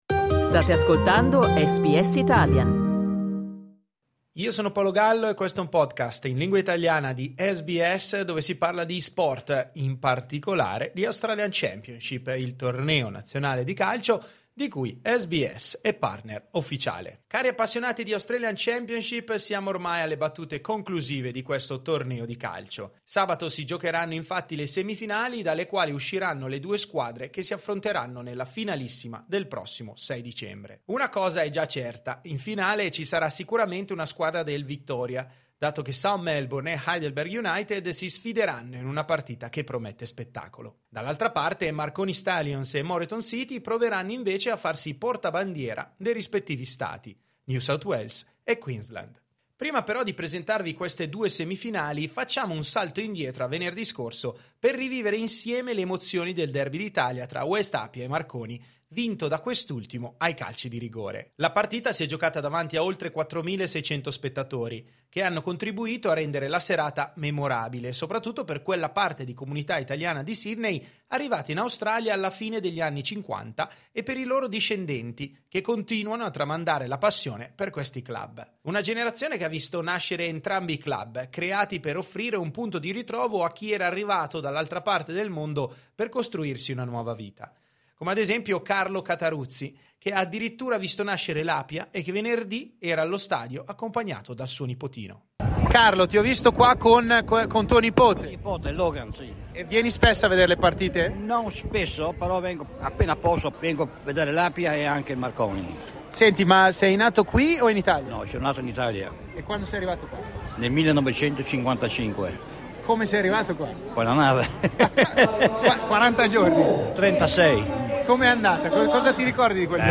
Sabato si giocheranno le semifinali, dalle quali usciranno le due squadre che si affronteranno nella finalissima del 6 dicembre. Prima però torniamo al Leichhardt Oval per ascoltare le voci dei tifosi.